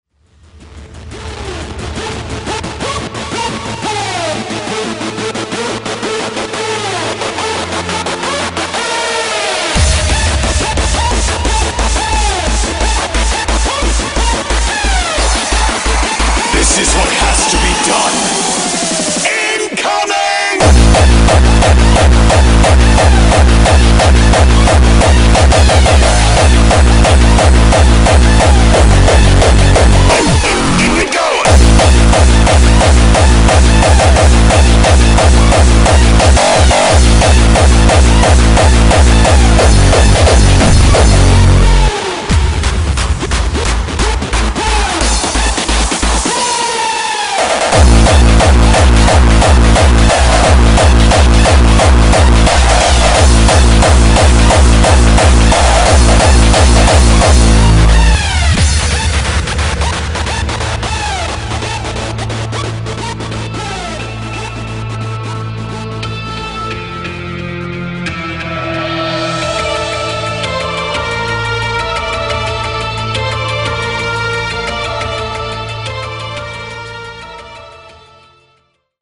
• Качество: 128, Stereo
Жестокий трэк в стиле ХАРД-БАС